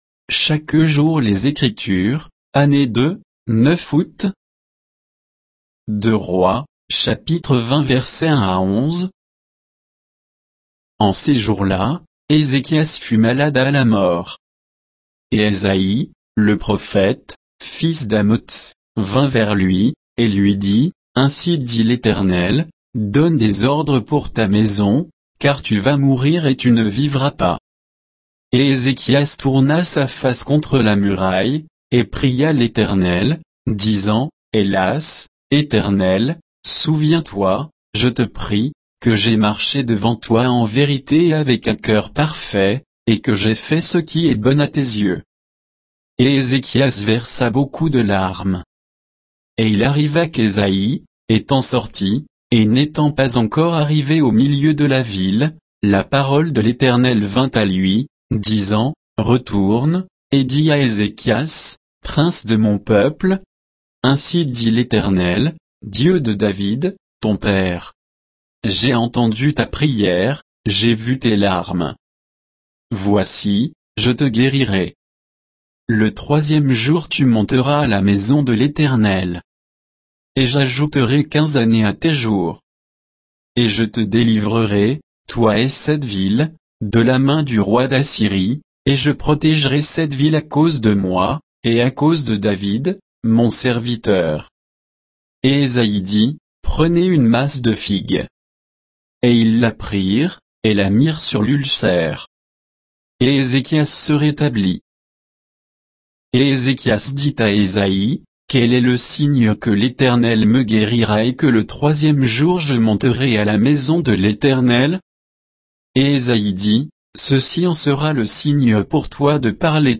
Méditation quoditienne de Chaque jour les Écritures sur 2 Rois 20